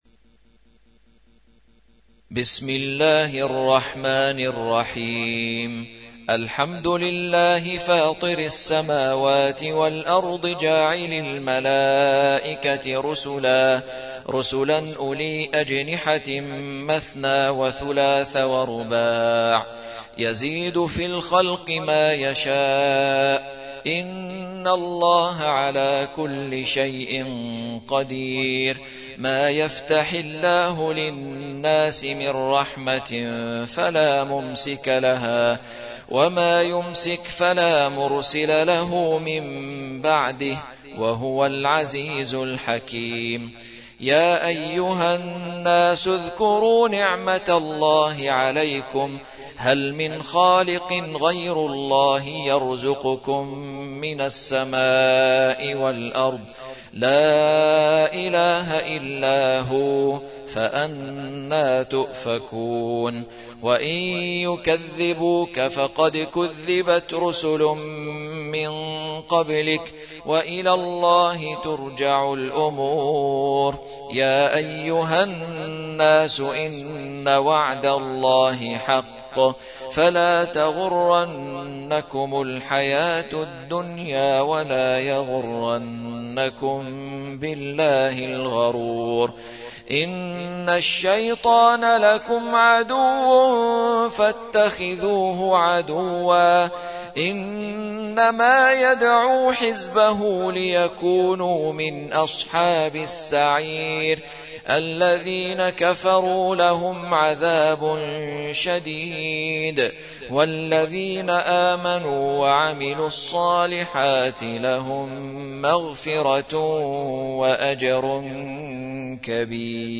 35. سورة فاطر / القارئ